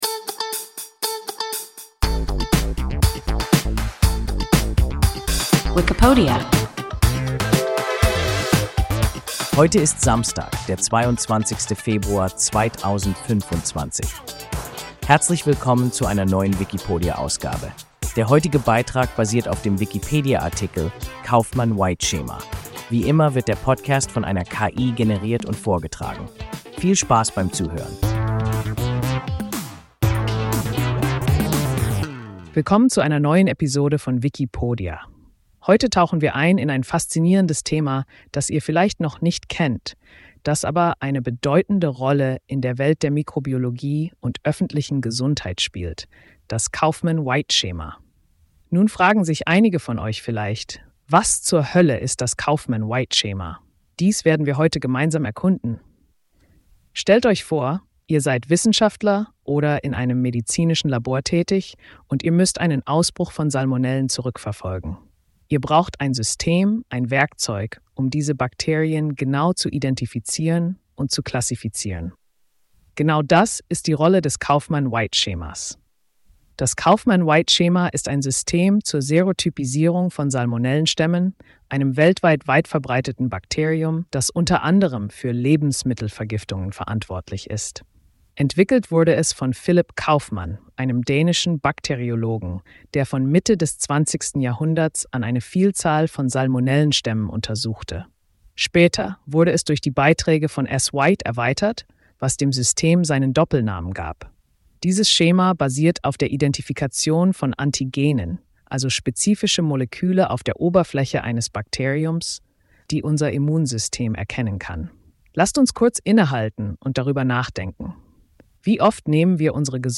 Kauffmann-White-Schema – WIKIPODIA – ein KI Podcast